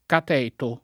cateto [ kat $ to ] s. m.